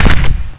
sack_hit.wav